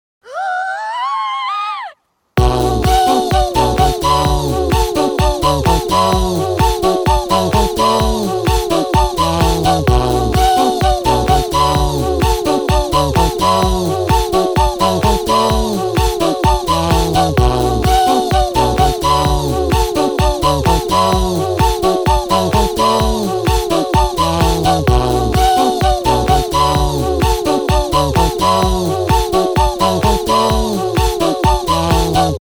• Качество: 320, Stereo
веселые
спокойные